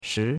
shi2.mp3